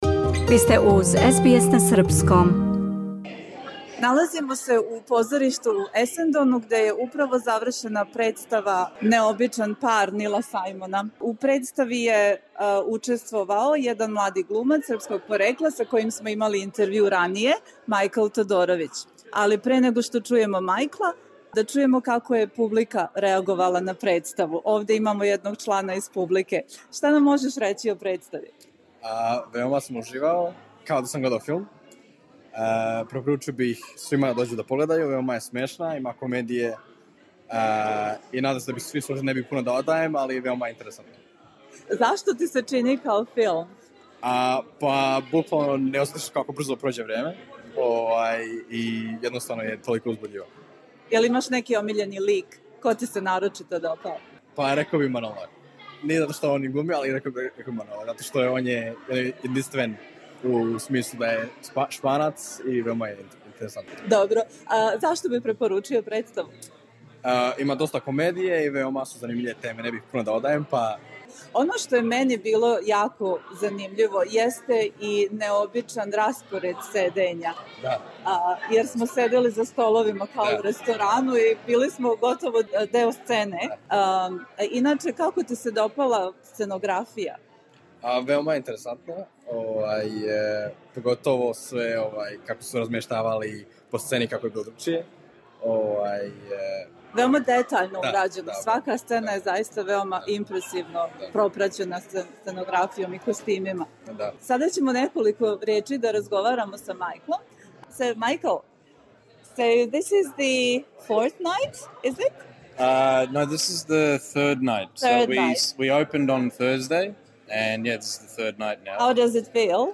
the-odd-couple-interview-theatre-english-podcast.mp3